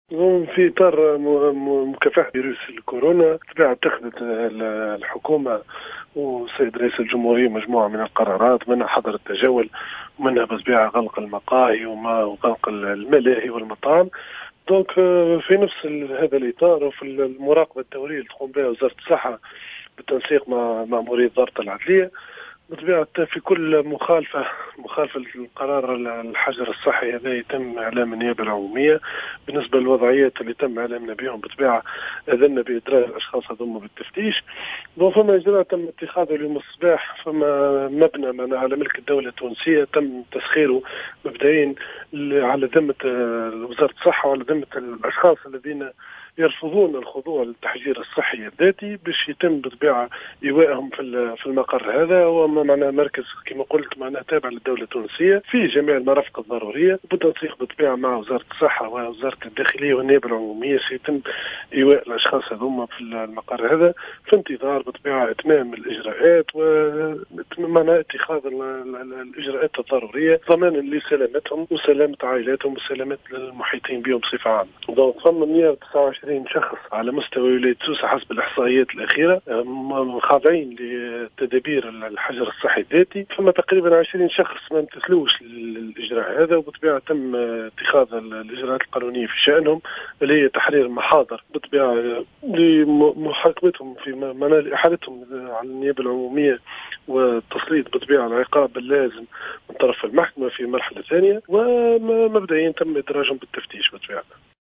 في تصريح للجوهرة "اف ام ".